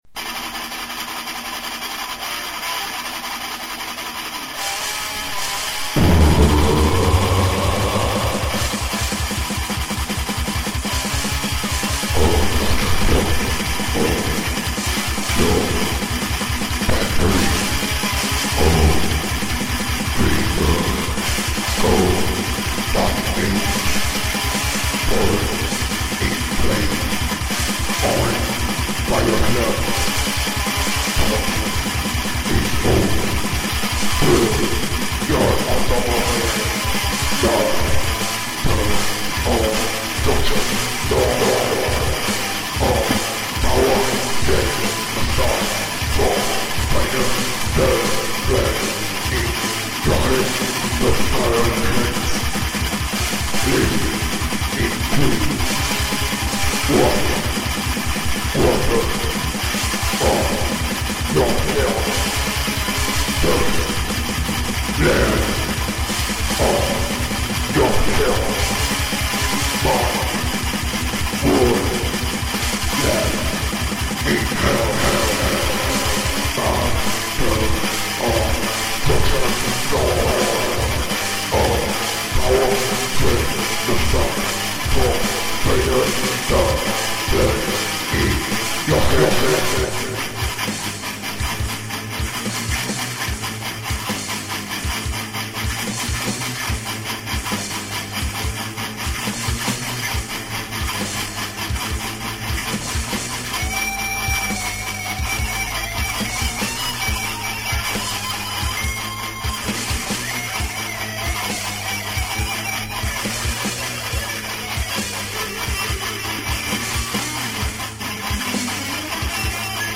EstiloThrash Metal